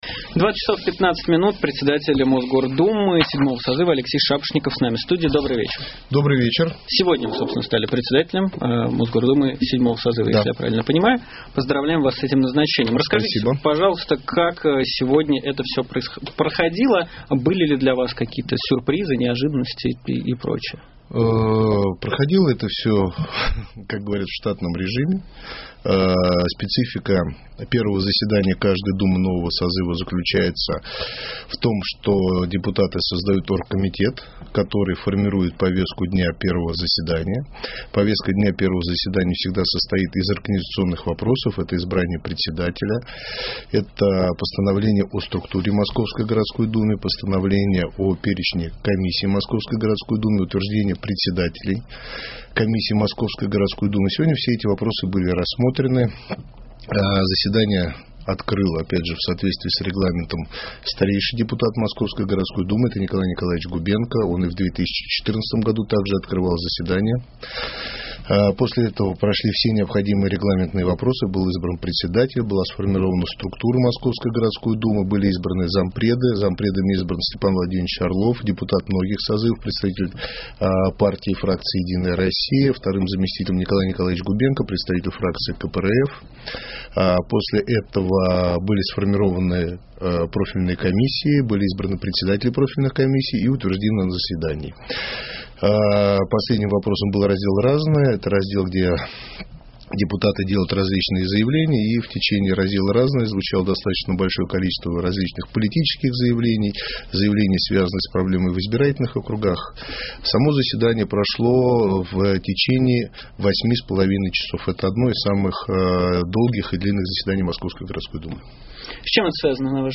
Алексей Шапошников, председатель Мосгордуму осеннего созыва с нами в студии.